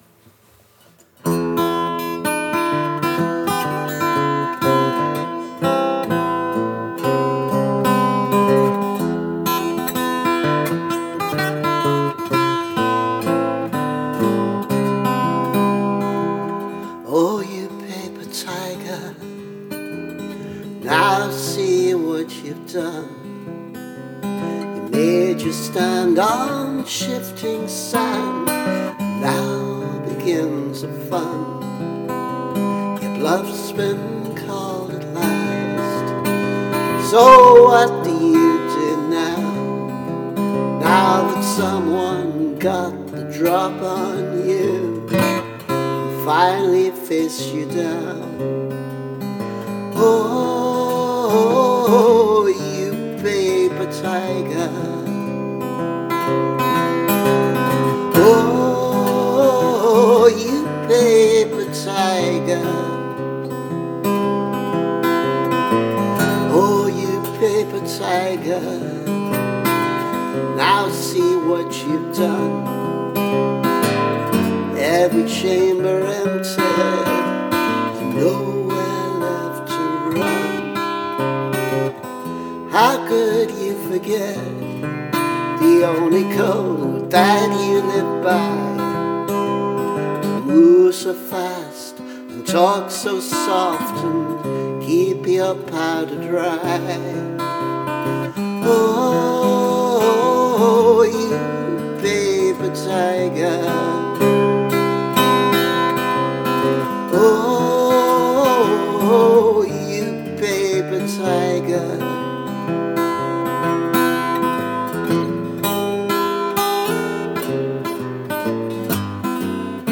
Paper Tiger [rough demo]